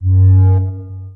warning1.wav